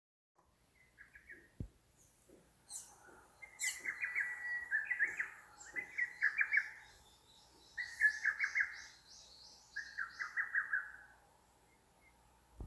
제주도에서 새소리를 녹취하고 있습니다.
파일중에서 '휙휙 휙휙휙휙' 이렇게 6번~7번 계속 반복적인 노래를 부르는 새소리가 궁금합니다.
메인 소리를 말씀하시는 거라면 두견이 소리입니다 ^^
두견이 소리입니다